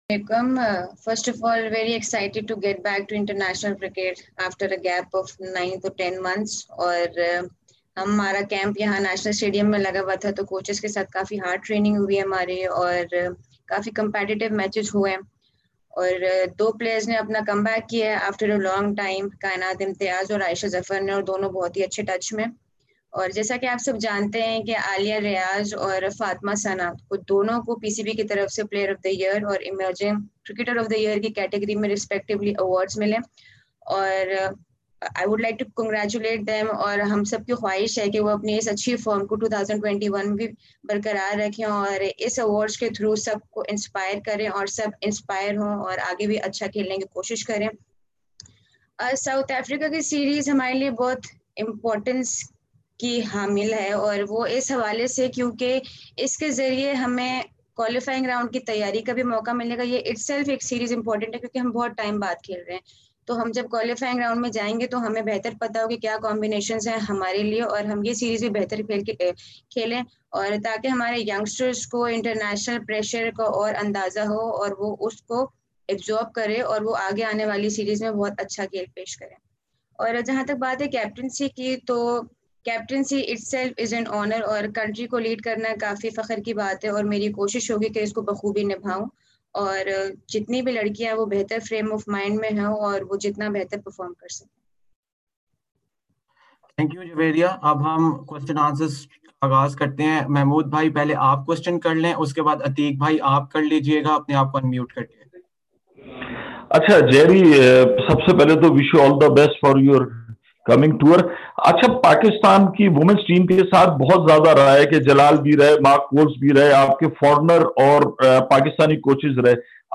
Javeria Khan, the captain of the Pakistan women’s national team for the South Africa tour, held a pre-departure virtual media conference today.